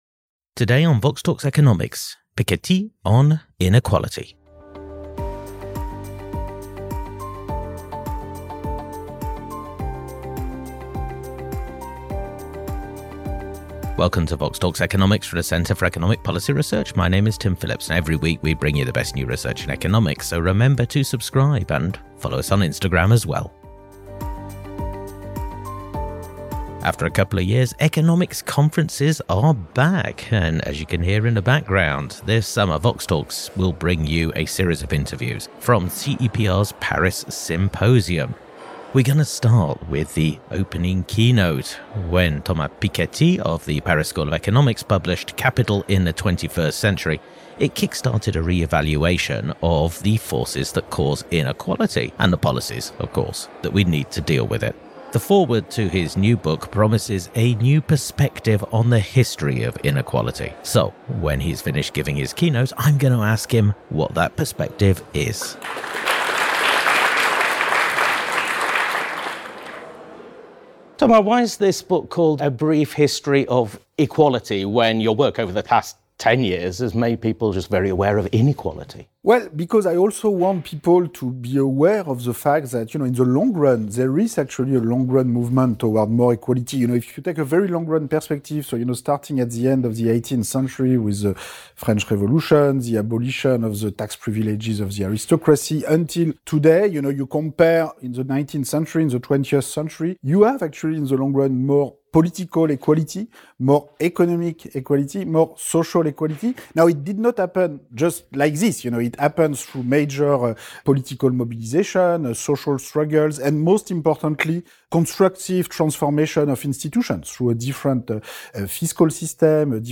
Headliner Embed Embed code See more options Share Facebook X Subscribe Recorded live at CEPR Paris Symposium 2022: Thomas Piketty’s short new book promises A brief history of equality.